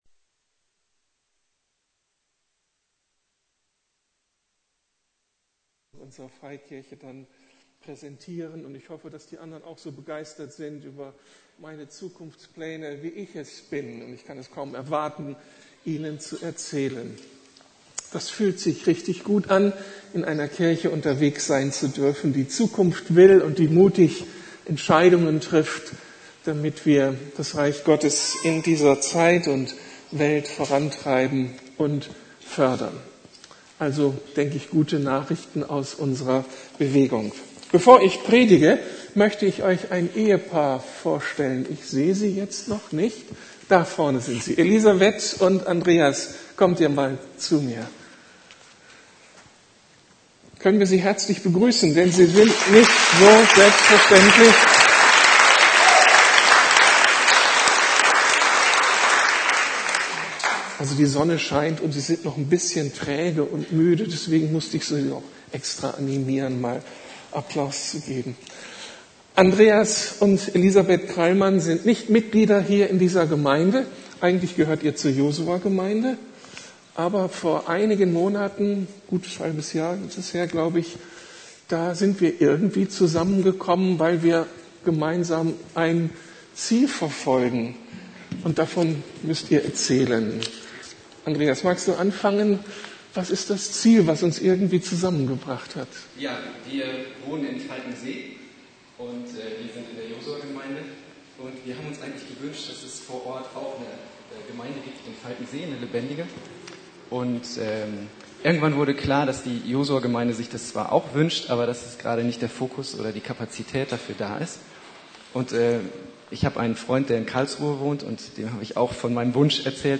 Worauf es wirklich ankommt.. ~ Predigten der LUKAS GEMEINDE Podcast